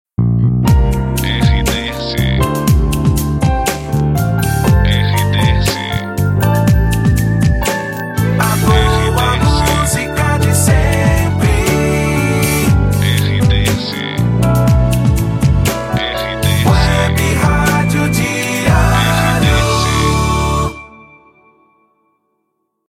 Saída de Bloco